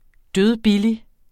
dødbillig adjektiv Bøjning -t, -e Udtale [ ˈdøðˈbili ] Betydninger meget billig SPROGBRUG uformelt Synonymer tæskebillig drønbillig Rapportér et problem fra Den Danske Ordbog Den Danske Ordbog .